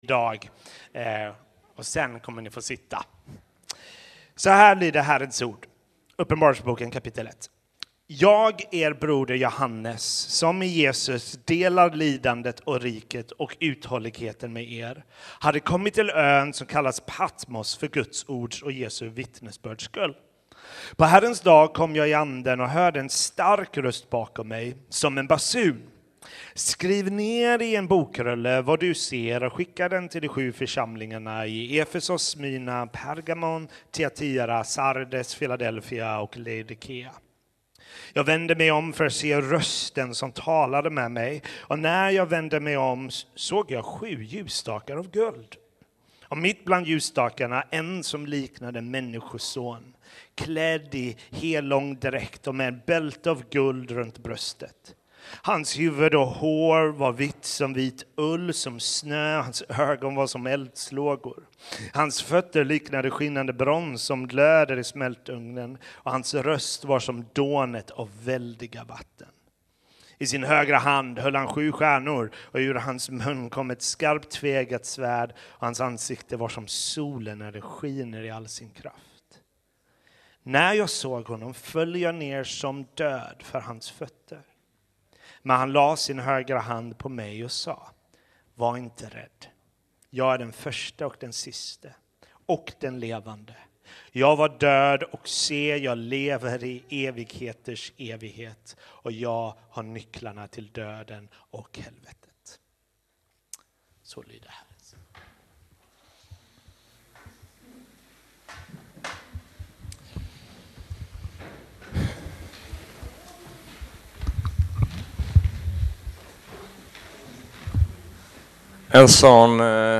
Sermons – CENTRO – Podcast